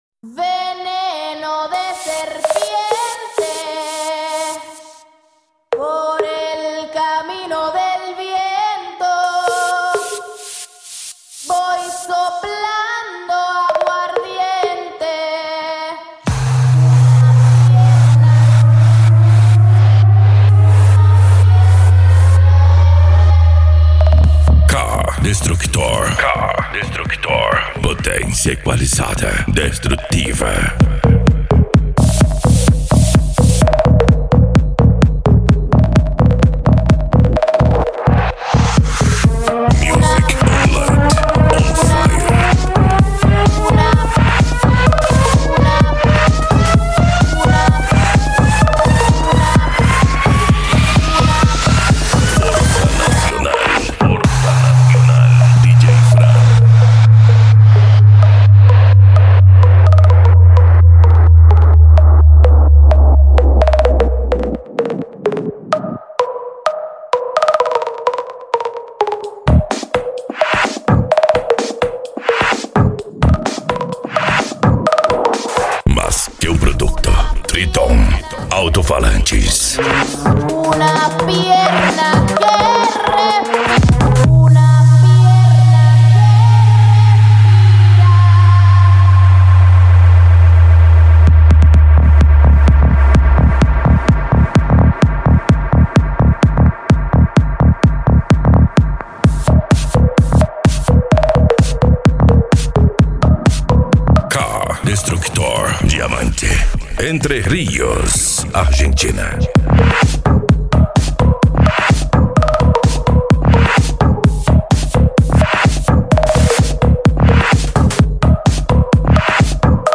Bass
Psy Trance
Remix